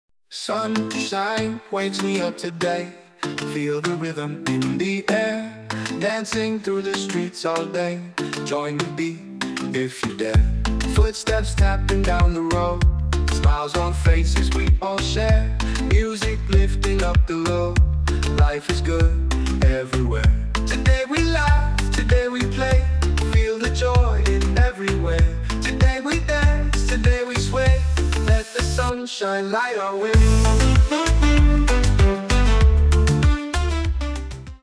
Reggae (island)